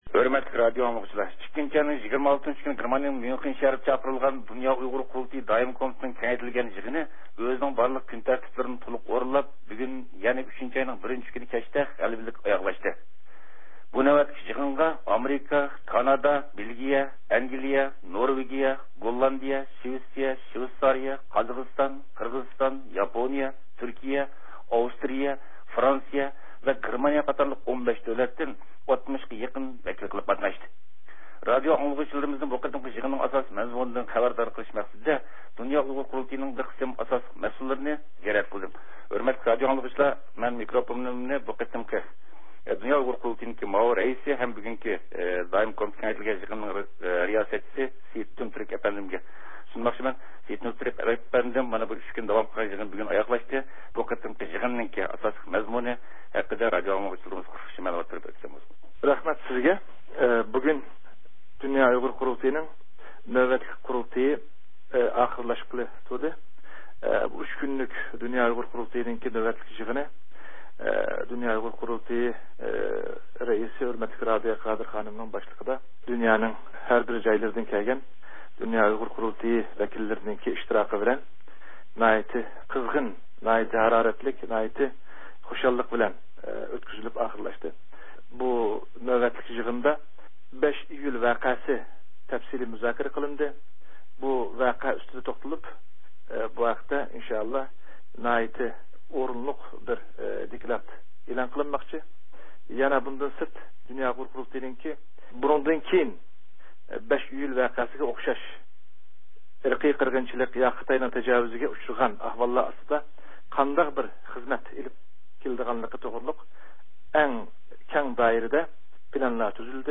بۇ مۇناسىۋەت بىلەن، نەق مەيداندا، بىر قىسىم قۇرۇلتاي رەھبەرلىرىنى قۇرۇلتاي توغرىسىدا زىيارەت قىلدۇق.